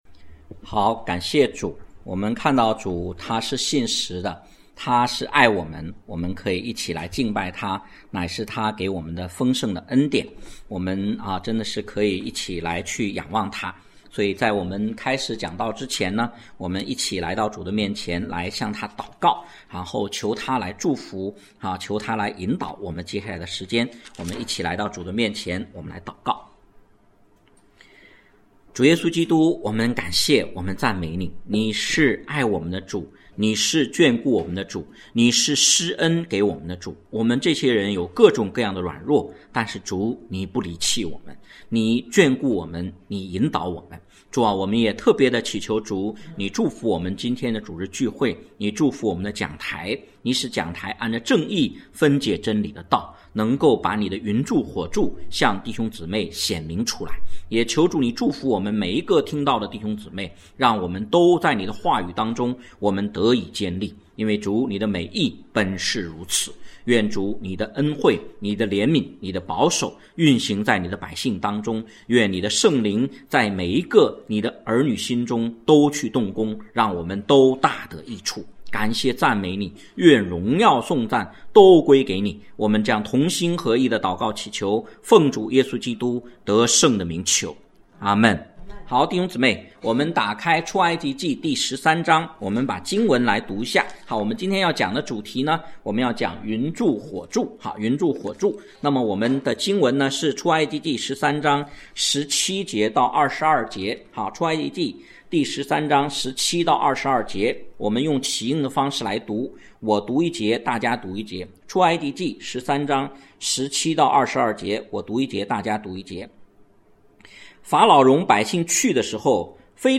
讲道经文